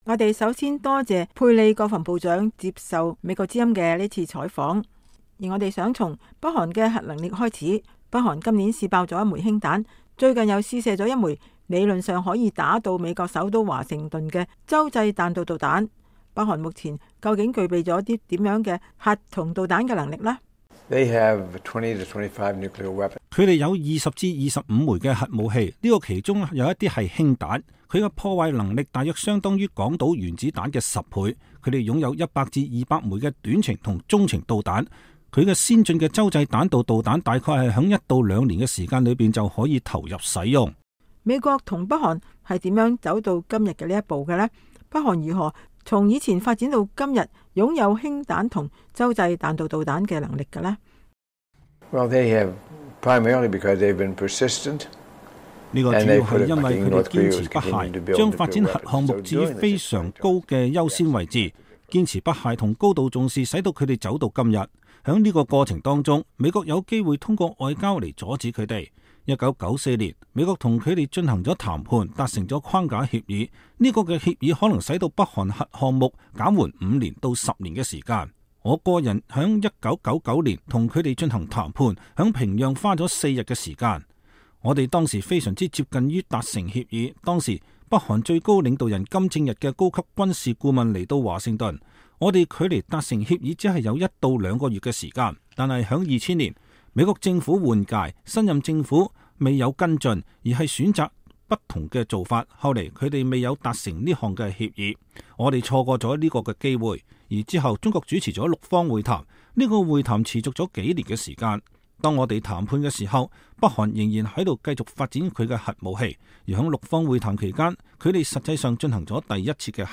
在北韓的核武器與導彈的威脅日益加劇之際，美國與南韓在朝鮮半島舉行了前所未有最大規模的聯合軍事演習。在朝鮮半島局勢劍拔弩張之際，曾經與北韓進行過直接談判的美國第19任國防部長佩里(William Perry)星期二接受了美國之音中文部記者的專訪。